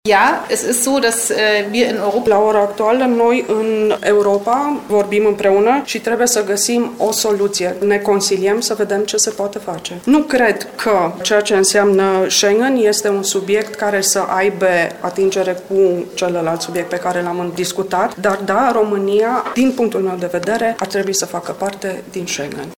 România trebuie să facă parte din Schengen, a declarat, la Timișoara, vicepreședintele Parlamentului Germaniei, Katrin Göring-Eckardt.